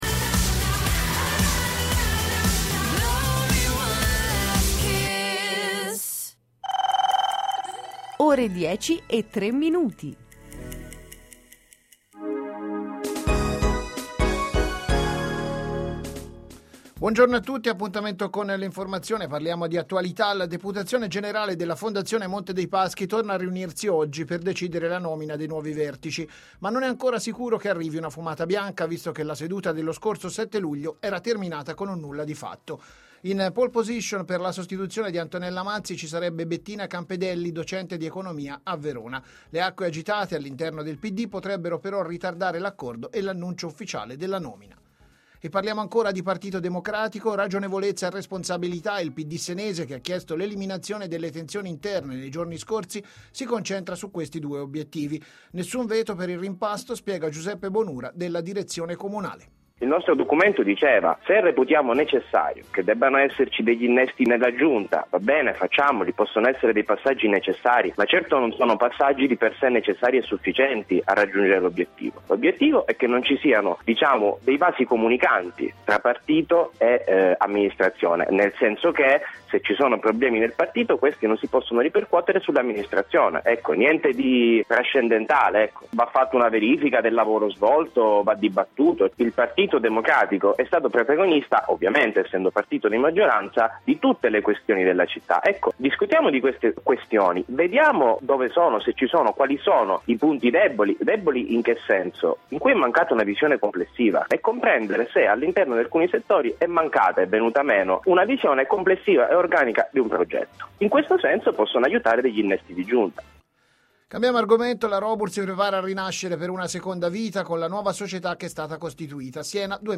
Notiziario ore: 10.00 - Antenna Radio Esse